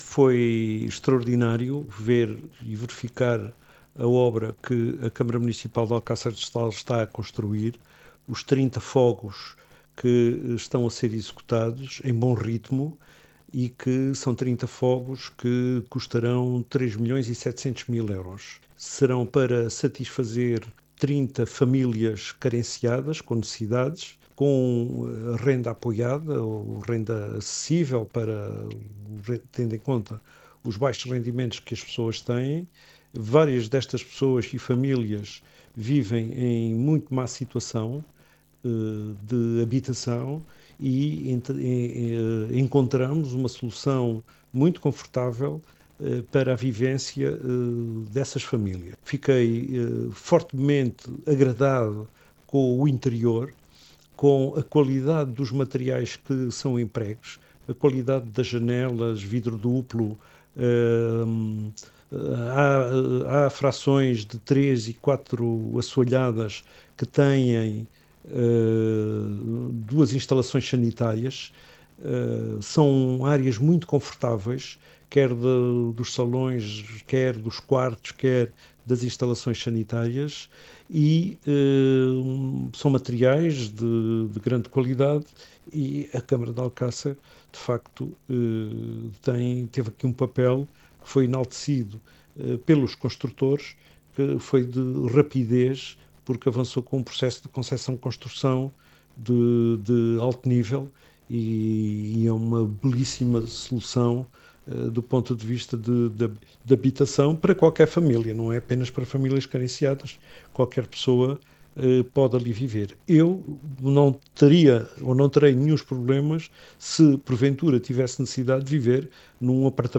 (entrevista TDS a Vítor Proença, pres. da C.M. de Alcácer do Sal)